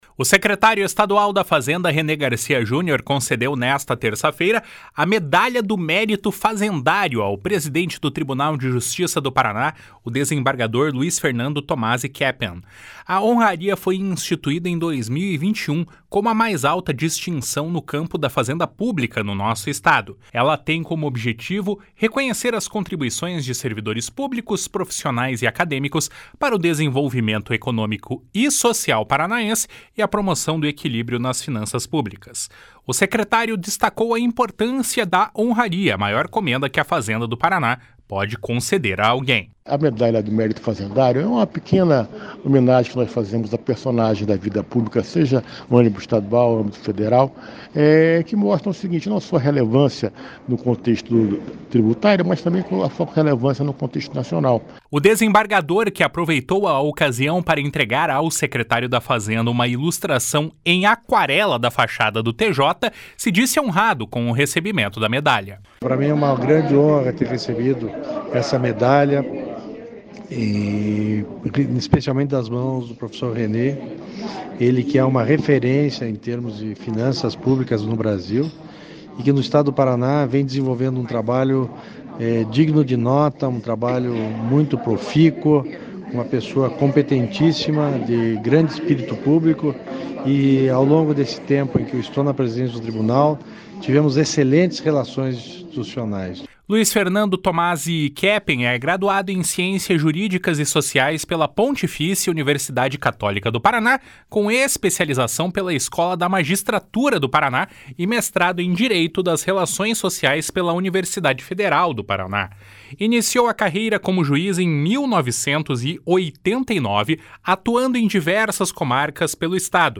// SONORA RENÊ GARCIA JUNIOR //
// SONORA LUIZ FERNANDO TOMASI KEPPEN //